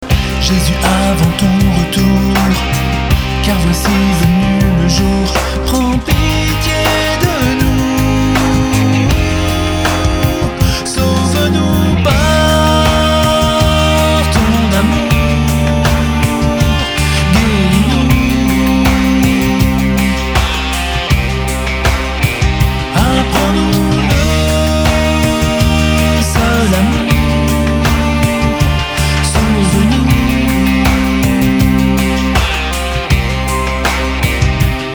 Pop/Rock francophone (101)